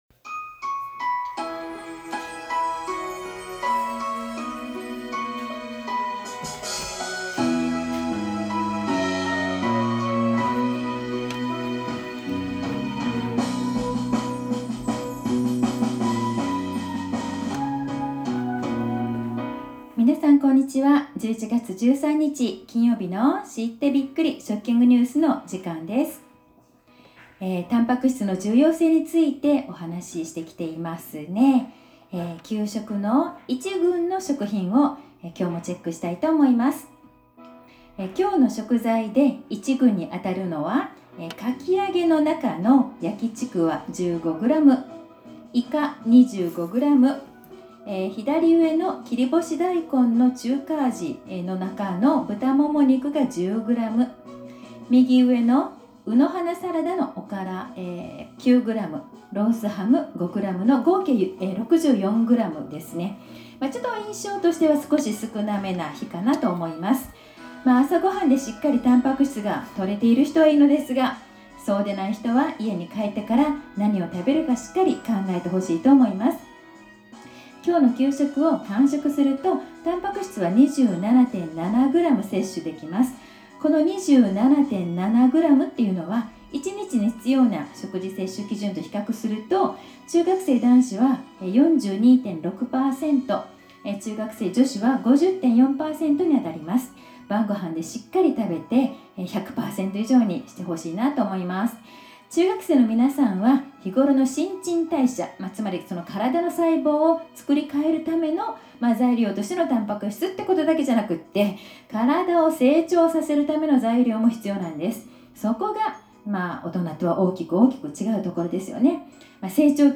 放送では，前日同様，１群の食品チェックと細胞の入れ替わりについて話しています。